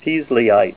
Say PEISLEYITE